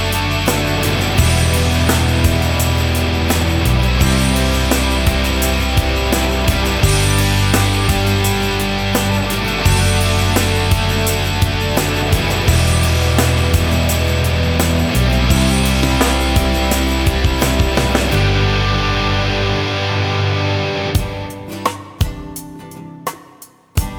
no Backing Vocals R'n'B / Hip Hop 4:12 Buy £1.50